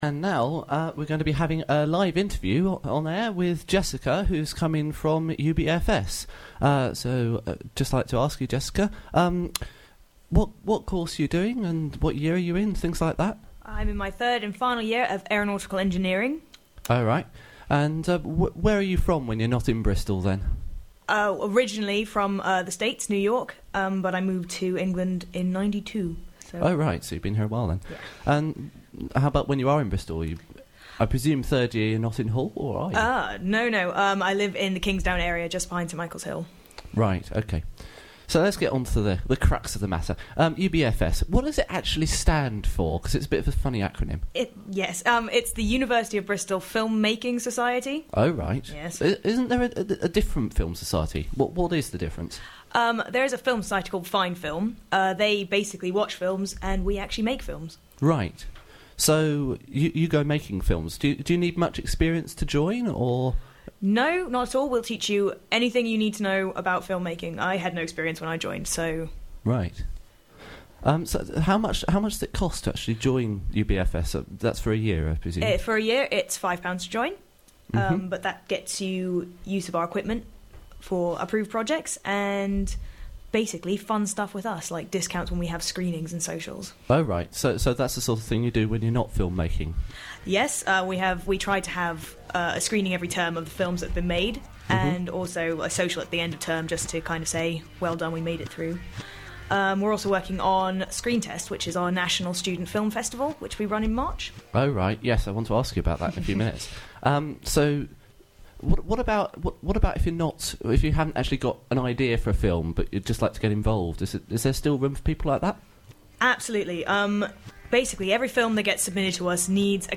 Interviews from the Featured First show
Featured First is BURST's new Speech show for Friday lunchtimes.